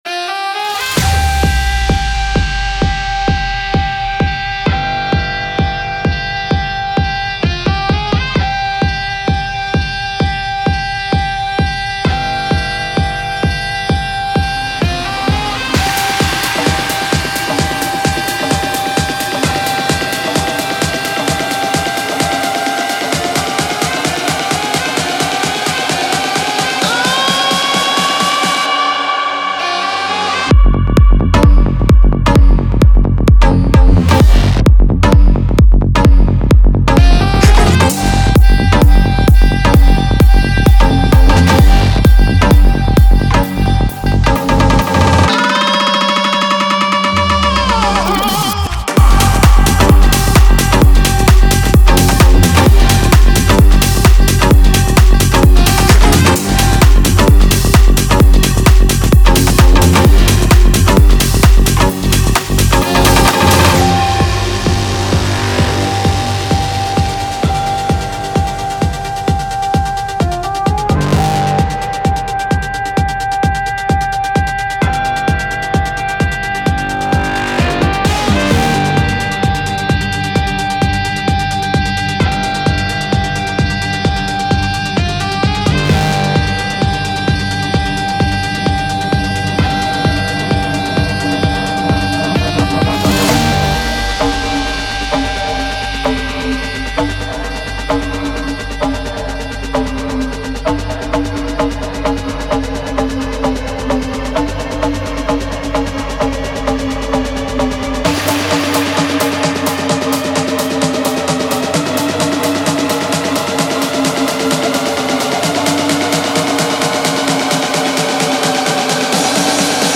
• Жанр: Techno, House